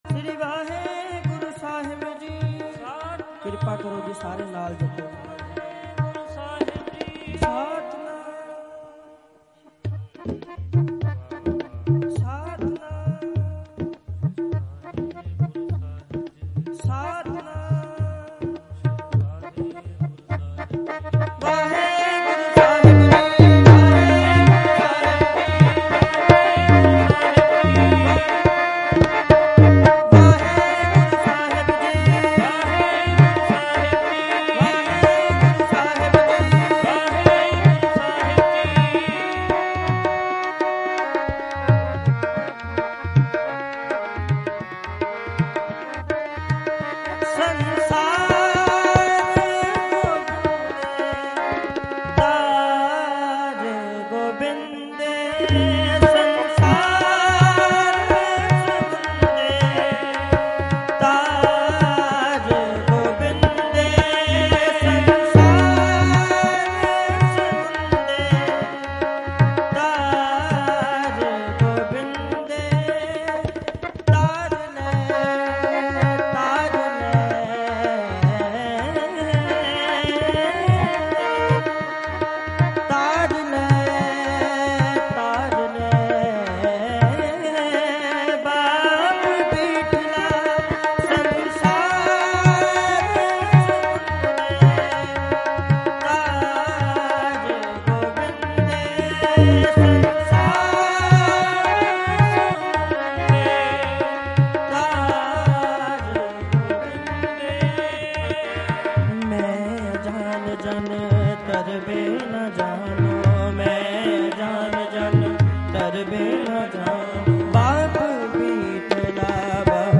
live-gurmat-samagam-phagwara-jalandhar-8-nov-2025-dhadrianwale.mp3